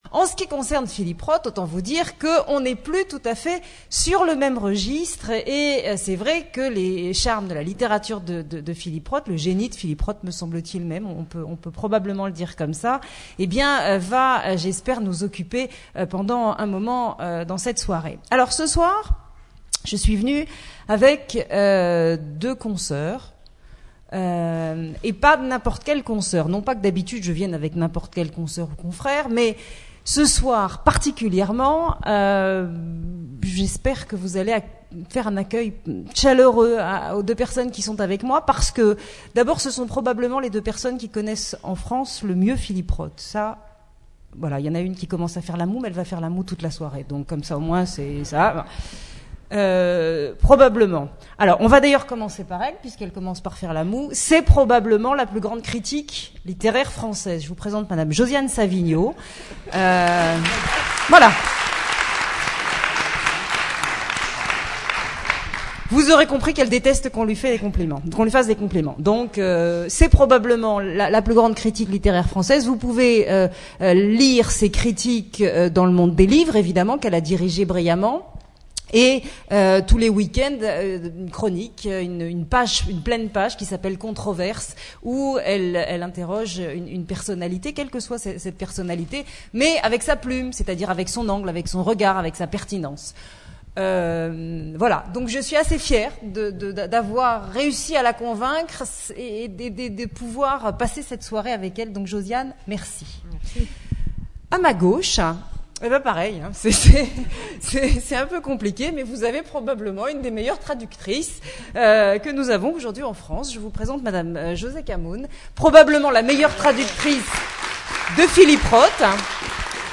Personne interviewée' en grand format
Rencontre littéraire